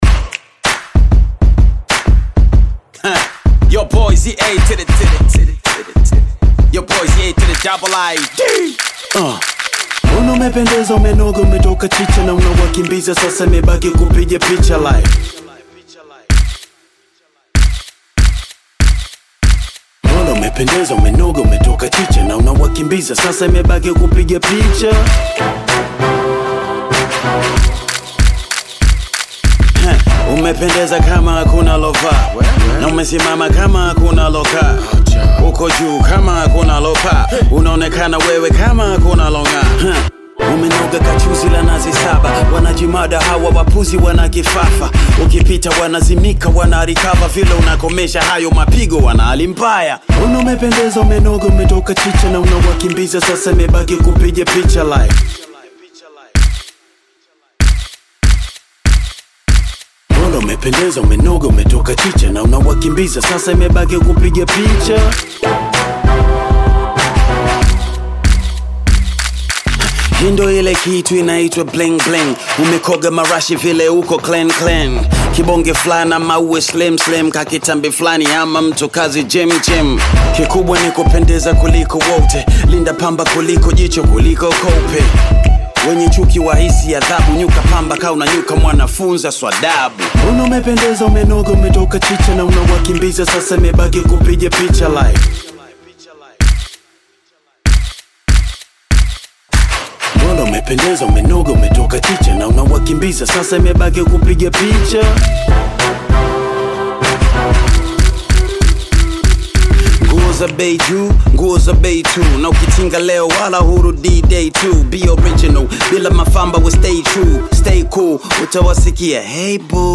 Tanzanian Bongo Flava artist, singer and songwriter